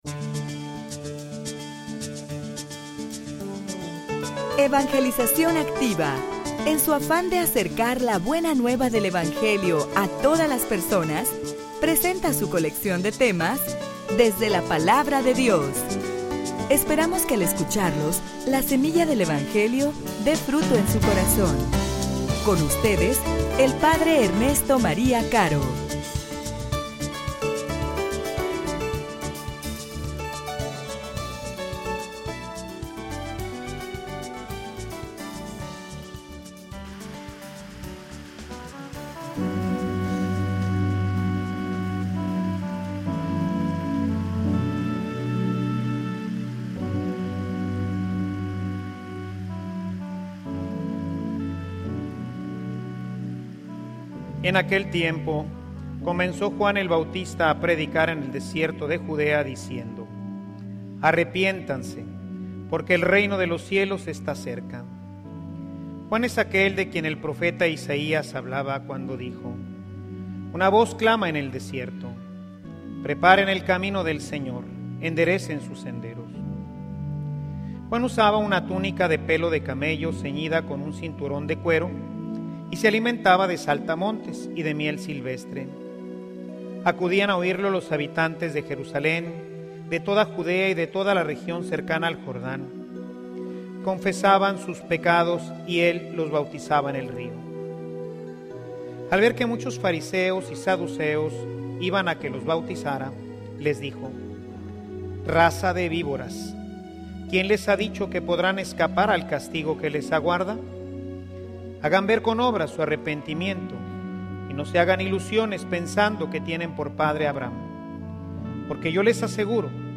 homilia_Y_tu_como_obedeces.mp3